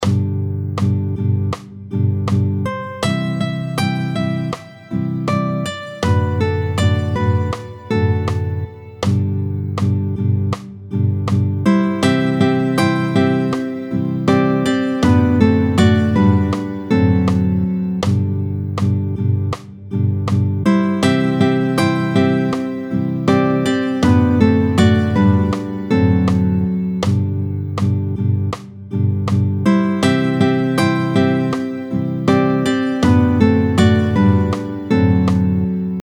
avec 3 voix, tempo 80